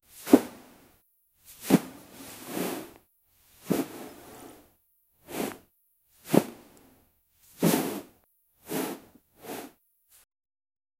The movements of the cloth echo on the surface of the sword with intense concentration, accompanied by a soft, precise sound.